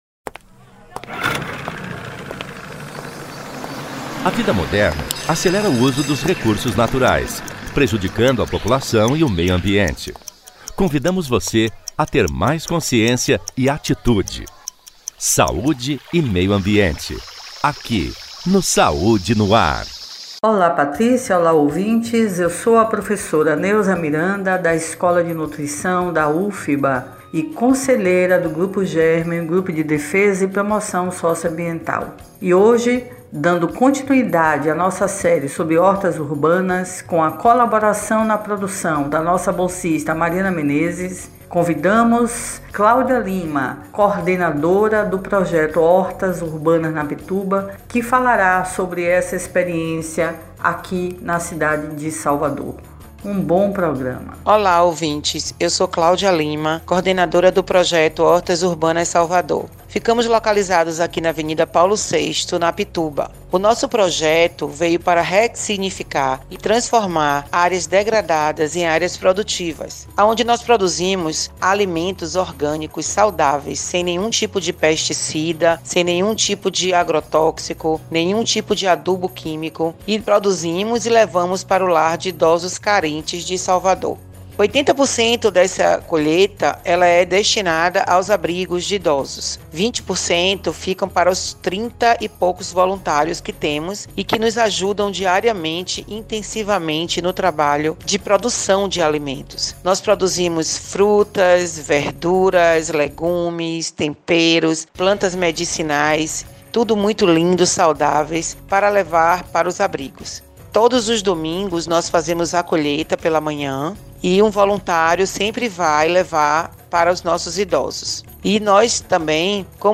O assunto foi tema do quadro “Meio Ambiente e Saúde”, veiculado às Quartas-feiras pelo programa Saúde no ar, com transmissão pelas Rádios Excelsior AM 840  e  Web Saúde no ar.